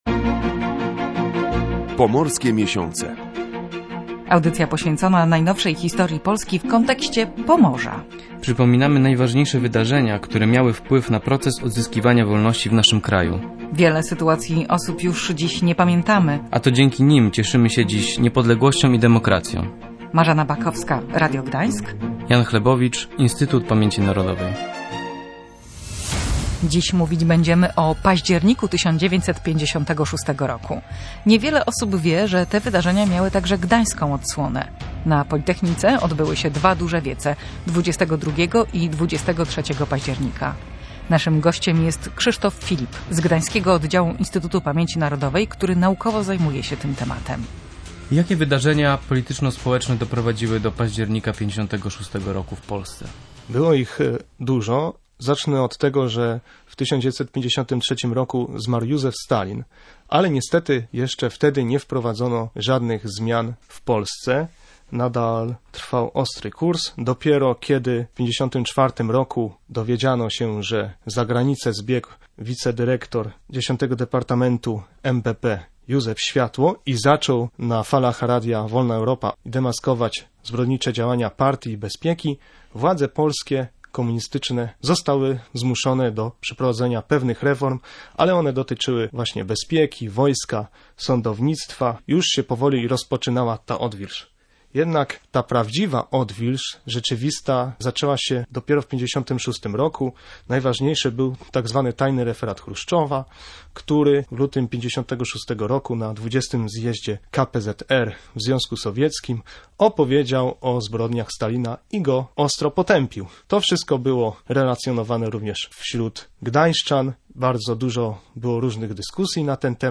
Audycja „Pomorskie miesiące” poświęcona jest najnowszej historii Polski w kontekście Pomorza.